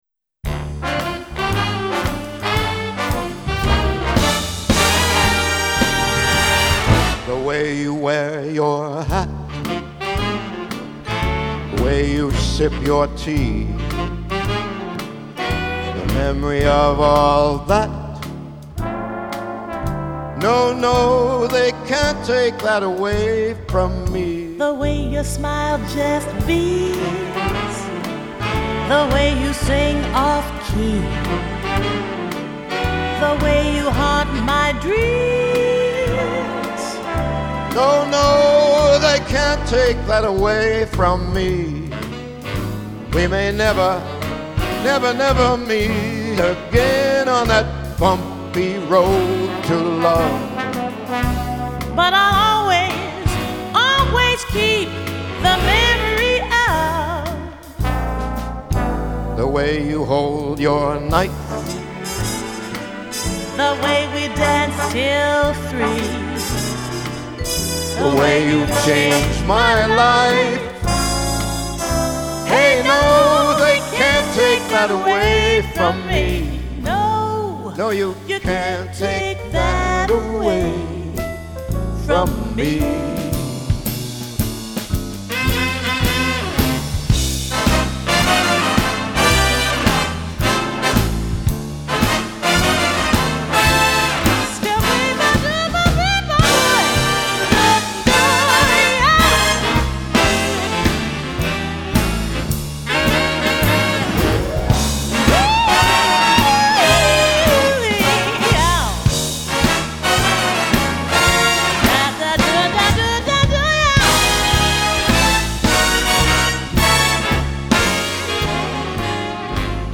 Duet